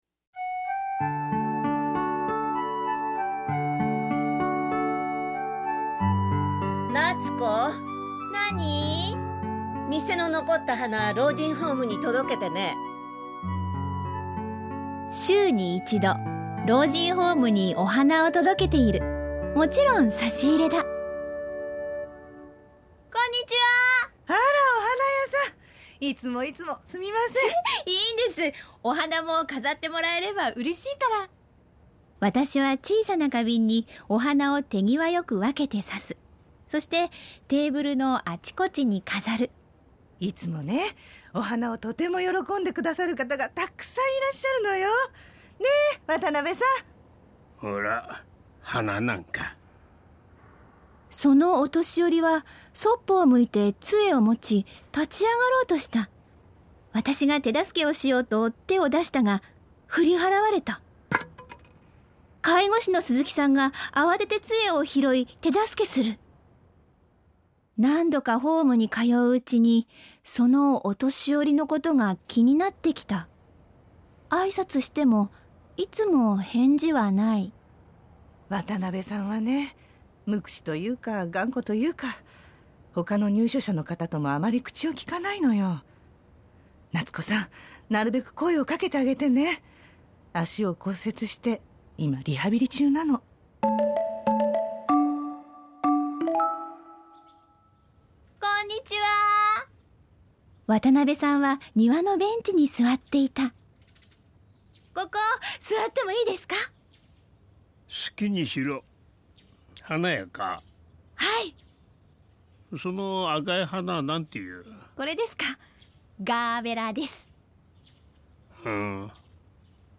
●ラジオドラマ「花ものがたり」
鈴木すずき（女性介護士）